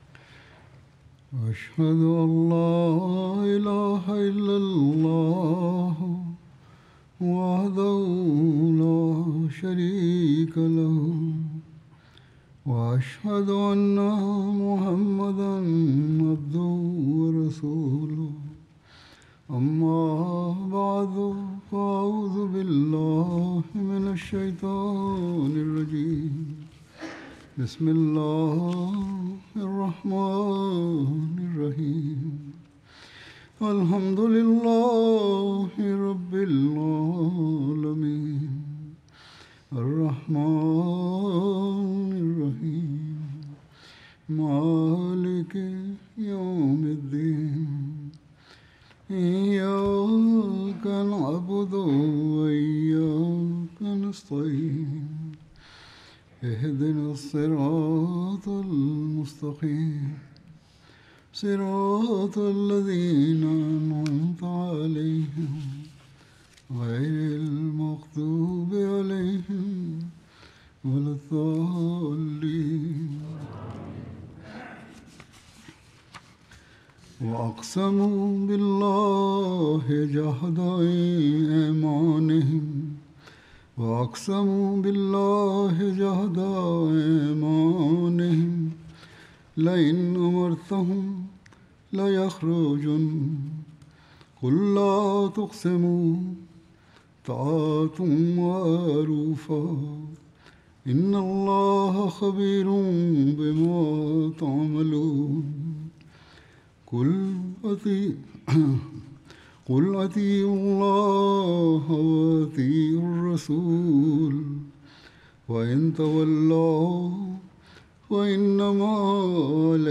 Urdu Friday Sermon delivered by Khalifatul Masih